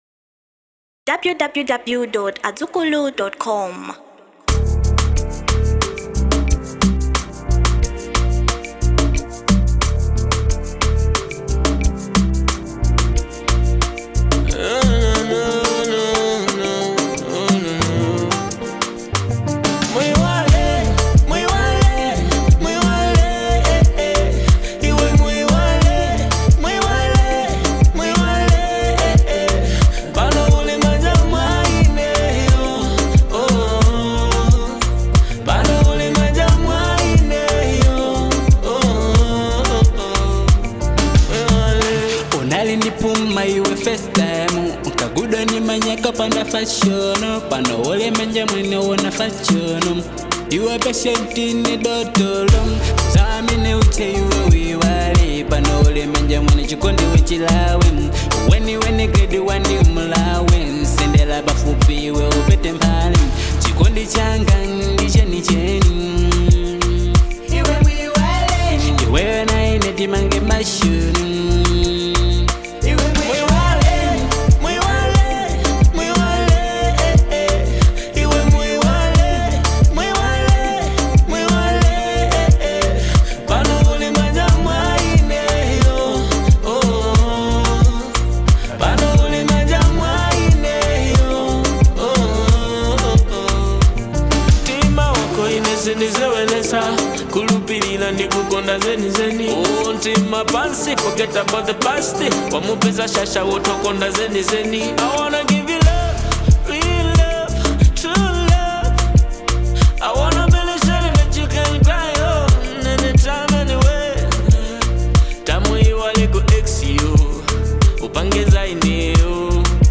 Genre DACEHALL